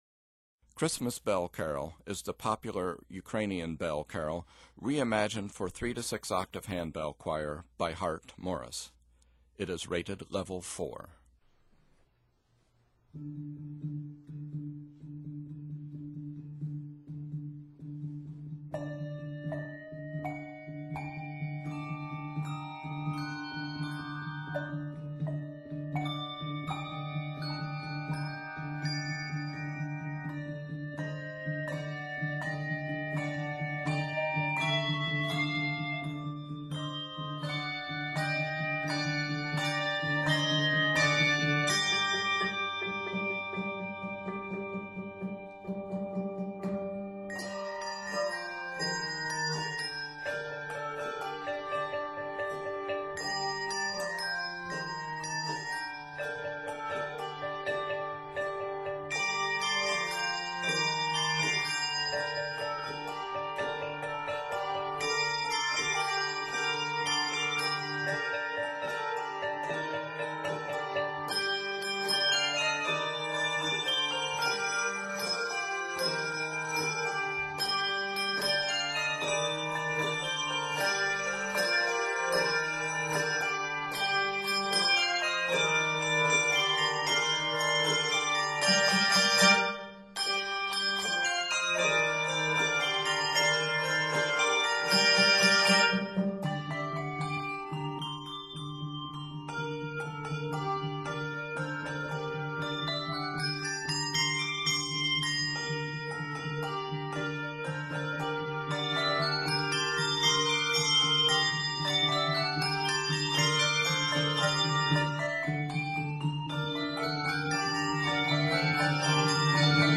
Rockin' out on old traditional carols?